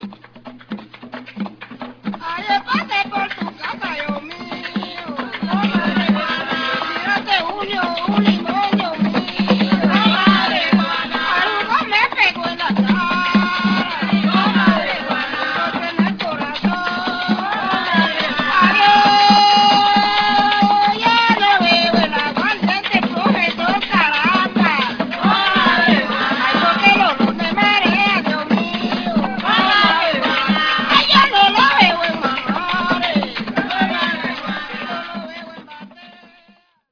Batería de tambores cónicos de dos membranas
Afrovenezolano, Región Central, Barlovento.
Intérpretes: Tamboreros de Vega Larga y Cantores de El Marqués y Aragüita
Ensamble: Prima, Cruzao, Pujao y voces
Característica: Con este trío de tambores se acompaña el canto y el baile para la celebración en honor a San Juan Bautista
Procedencia, año: Aragüita, Estado Miranda, Venezuela, 1974